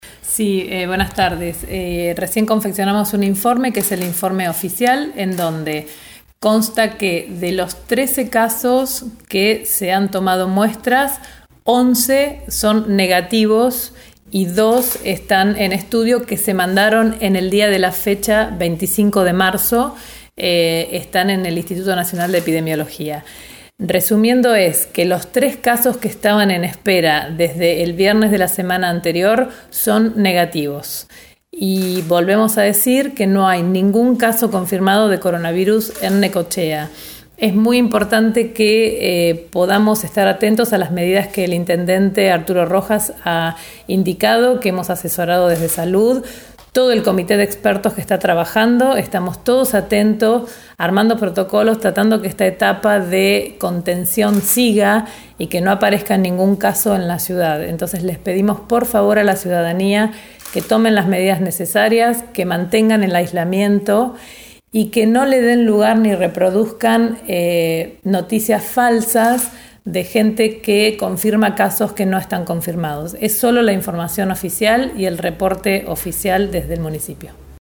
Así lo informó en el reporte oficial de este miércoles 25 de marzo la secretaria de Salud, Ruth Kalle, quien a la vez pidió a la ciudadanía seguir cumpliendo el aislamiento y no dar lugar a las noticias falsas.